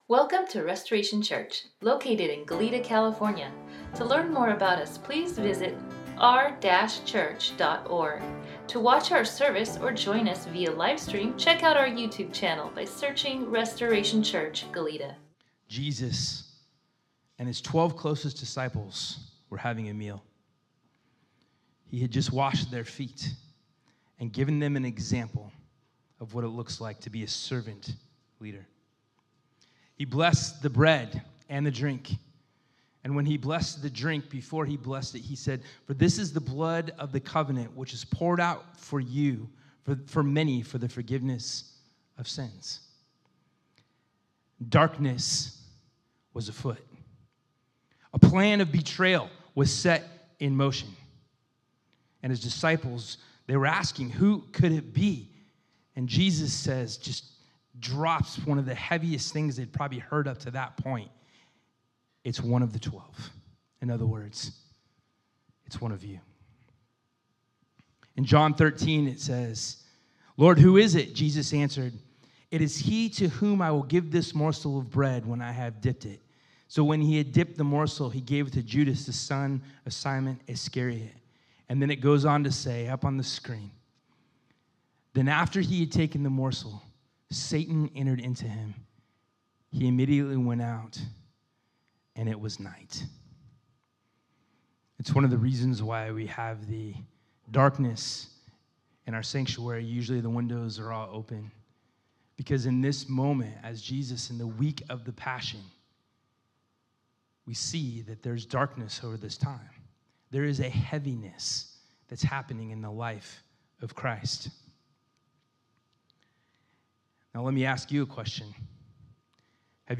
Sermon NotesDownload We are stoked that you are checking us out!